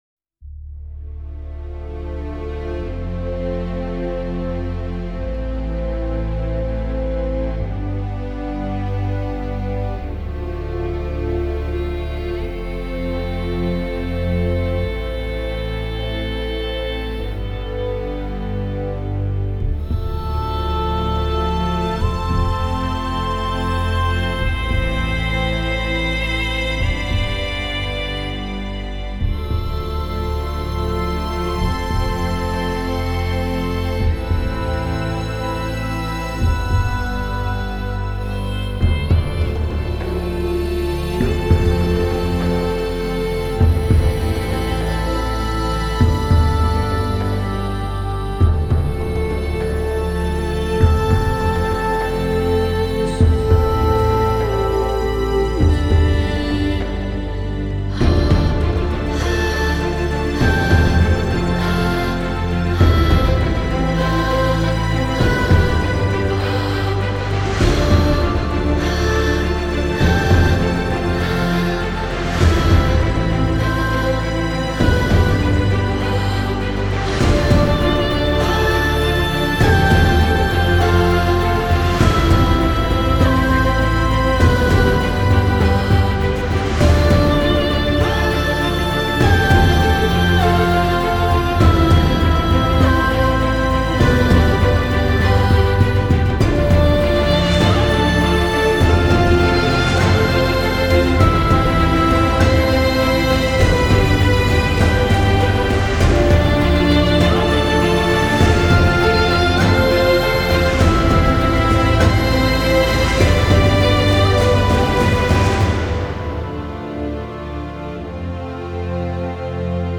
10 آهنگ حماسی و ارکسترال 24